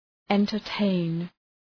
Προφορά
{,entər’teın}
entertain.mp3